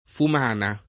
fumana [ fum # na ]